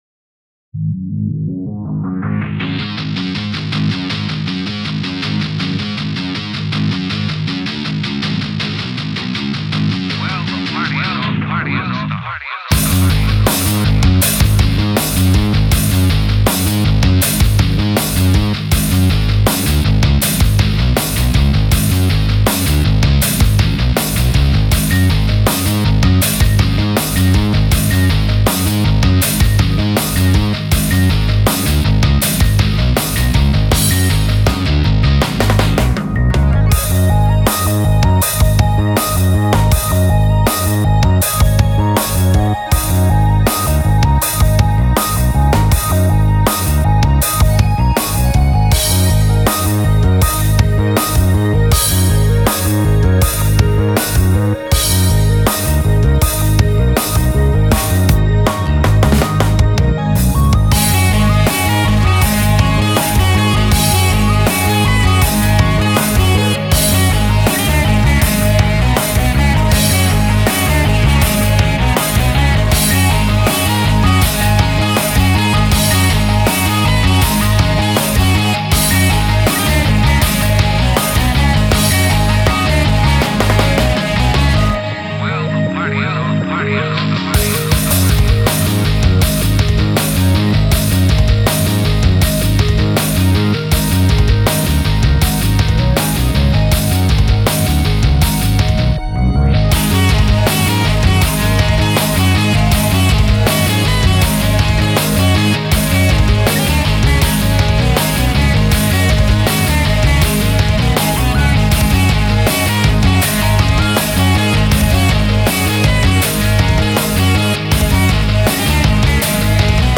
Home > Music > Ambient > Medium > Chasing > Restless